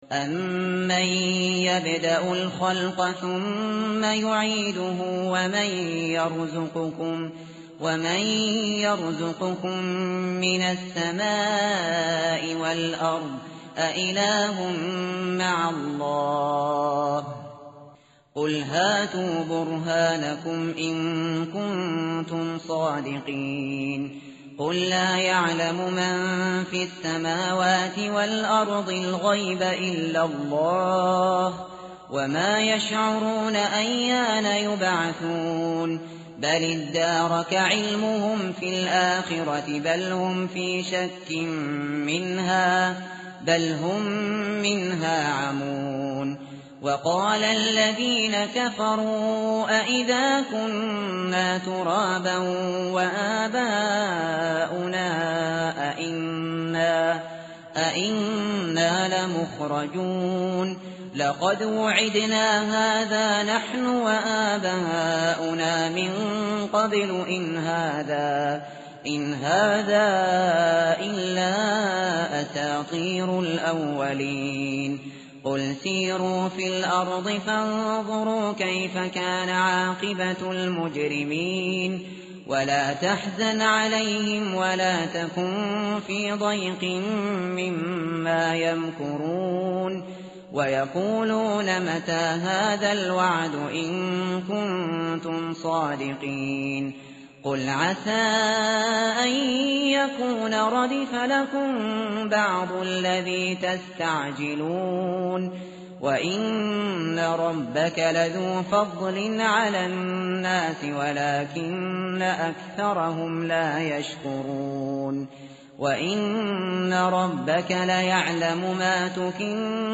متن قرآن همراه باتلاوت قرآن و ترجمه
tartil_shateri_page_383.mp3